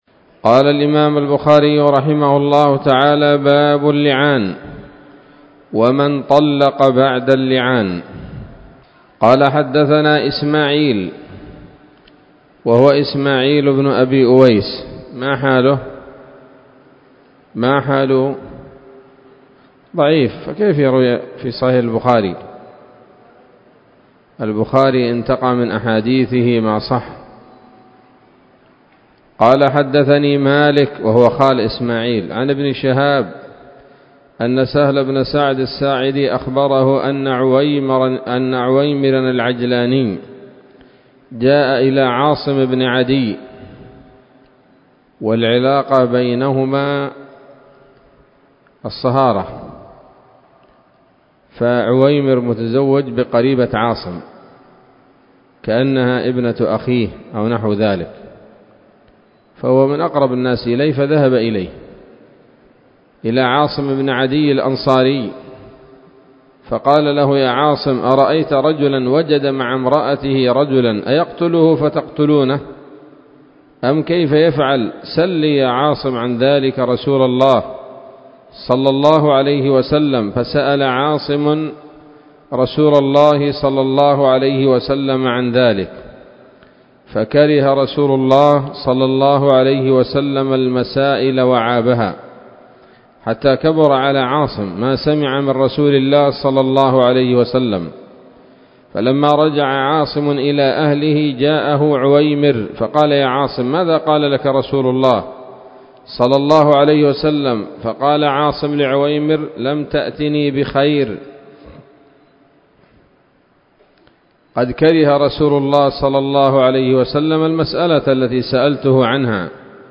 الدرس الثالث والعشرون من كتاب الطلاق من صحيح الإمام البخاري